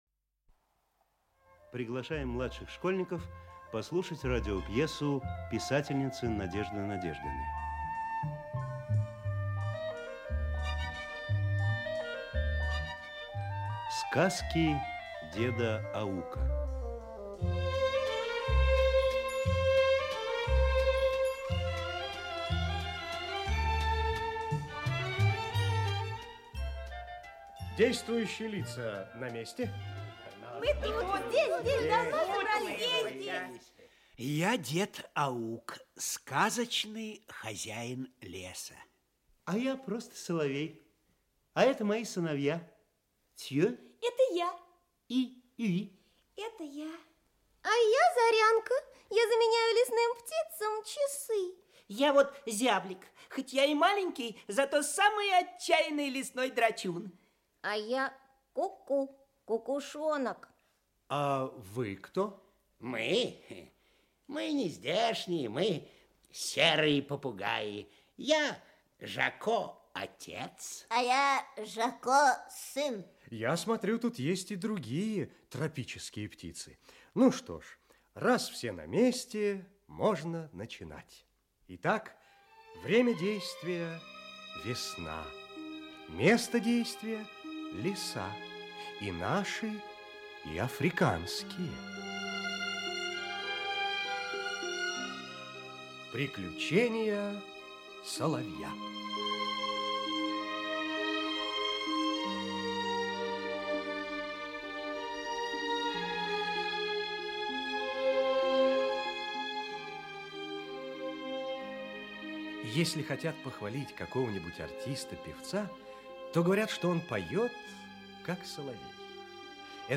Аудиокнига Приключения соловья | Библиотека аудиокниг